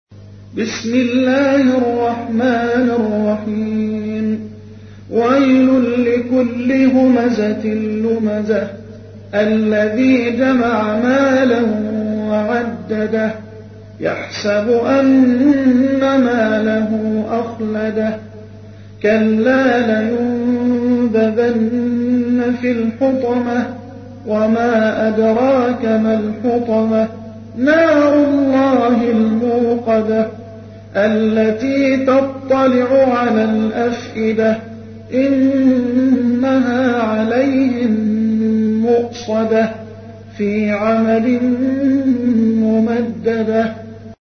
تحميل : 104. سورة الهمزة / القارئ محمد حسان / القرآن الكريم / موقع يا حسين